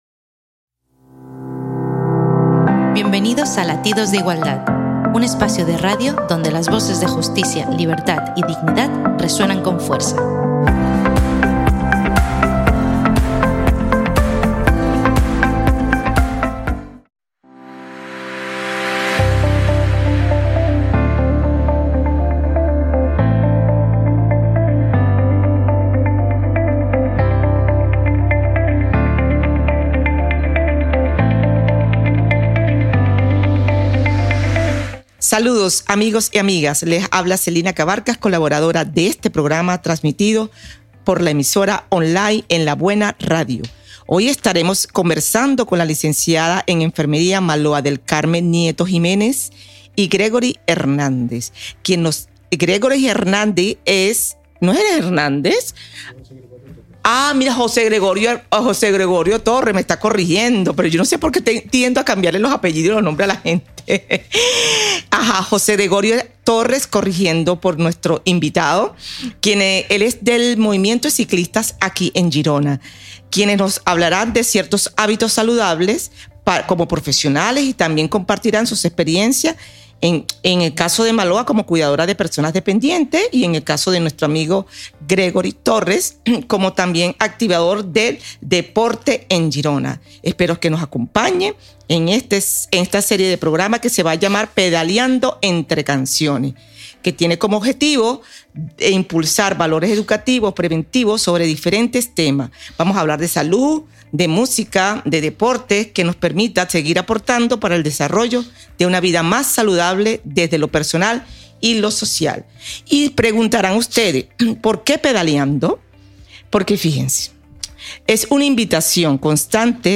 Entrevista
En aquesta xerrada